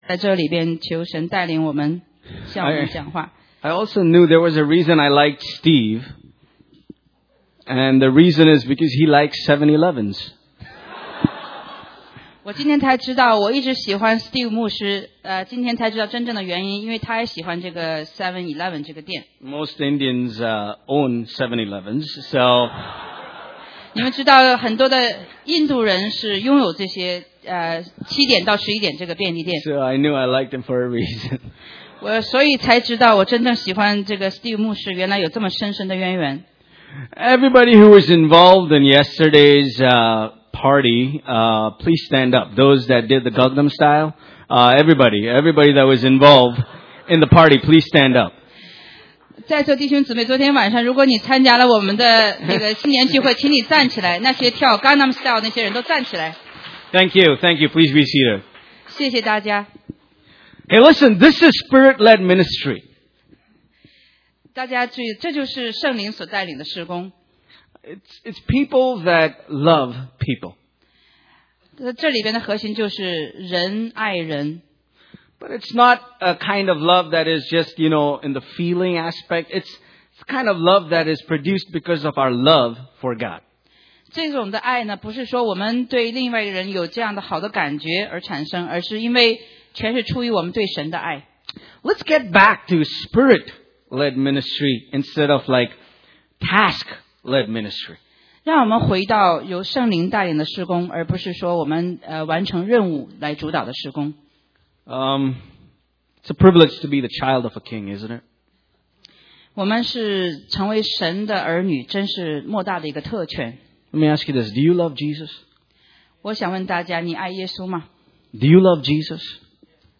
中文講道